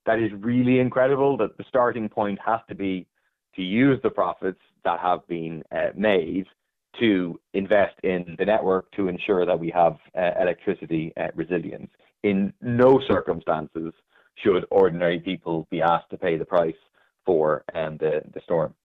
People Before Profit TD Paul Murphy says households simply shouldn’t have to pay for repairs: